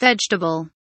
vegetable kelimesinin anlamı, resimli anlatımı ve sesli okunuşu